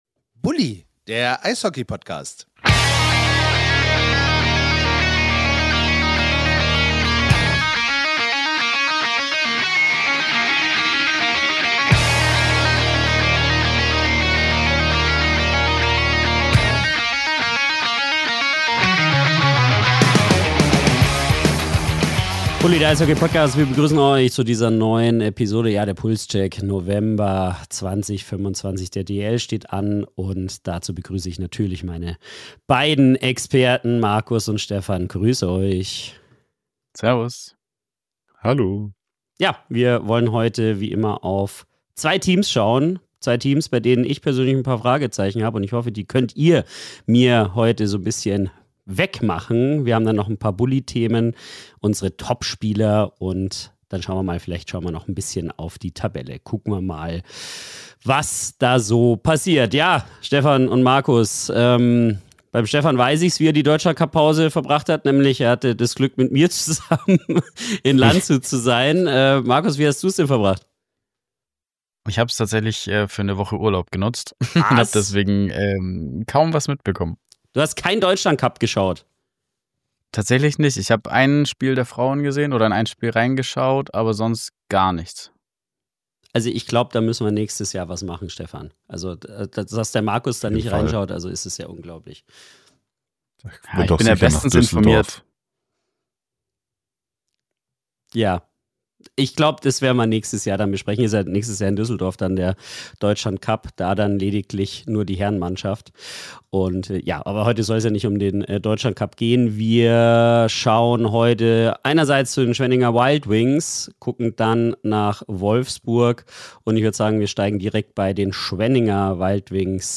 Mit fundiertem Fachwissen, klaren Einschätzungen und persönlicher Leidenschaft entsteht ein lebendiges Gespräch über die prägenden Themen der aktuellen Situation der Penny-DEL-Saison.